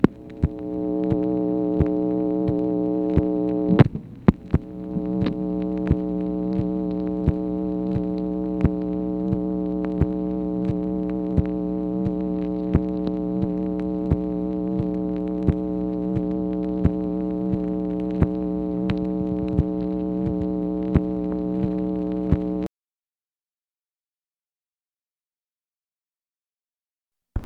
MACHINE NOISE, August 26, 1965